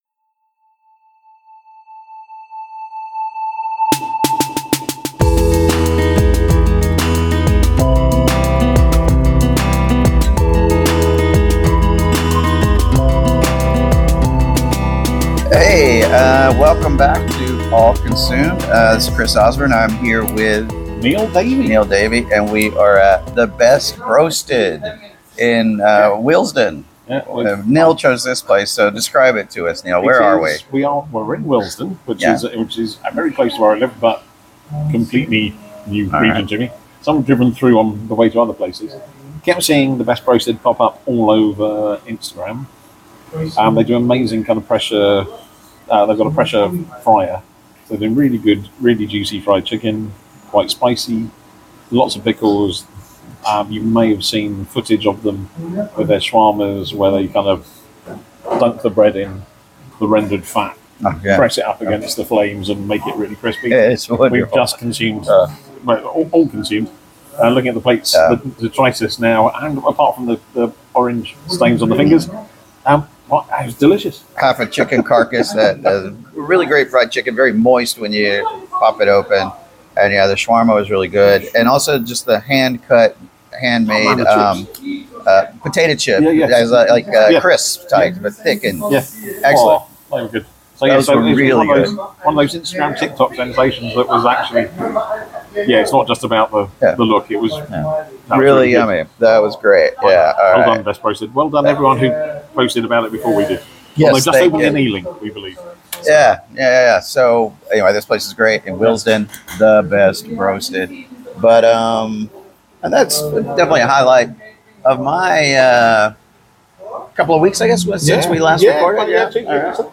While there, the duo recorded another episode of All Consumed, focusing on a few tasty and affordable places to eat around…